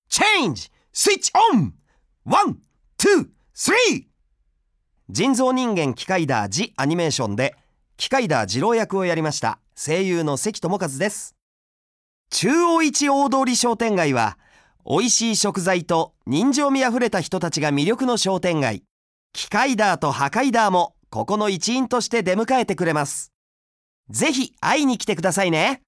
案内人：関智一さん
「ドラえもん」骨川スネ夫役